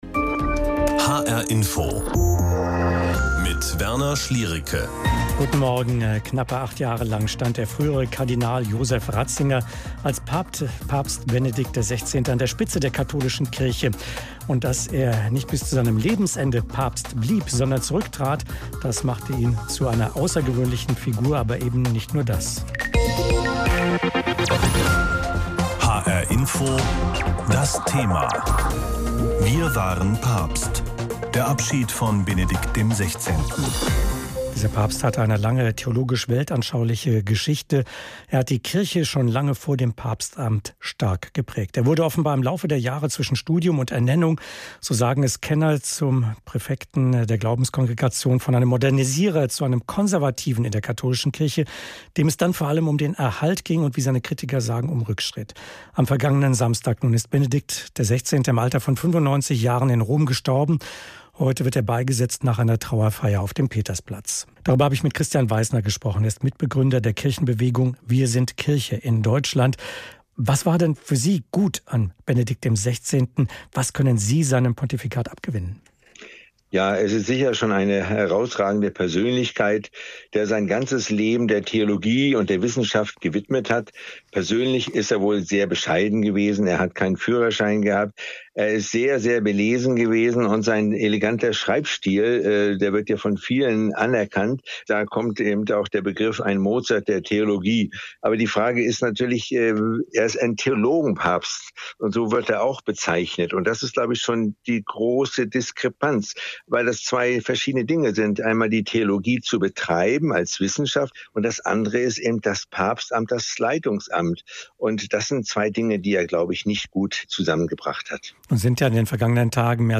Interview zum Tod von Joseph Ratzinger
hr-iNFO-Interview